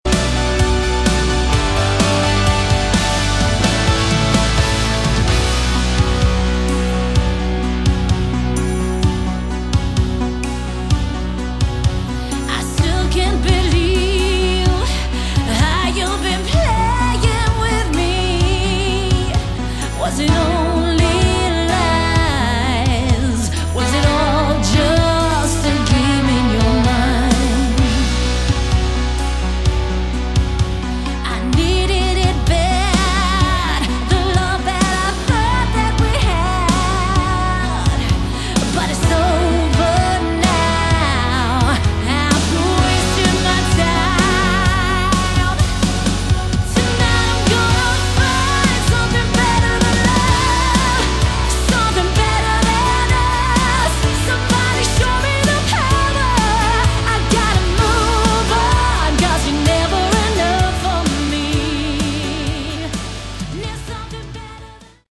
Category: 80s Hard Rock